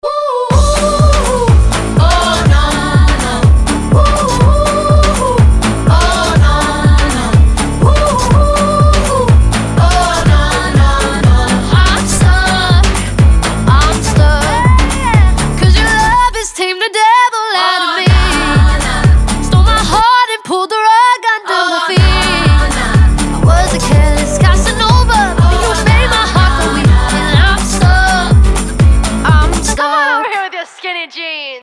• Качество: 320, Stereo
поп
позитивные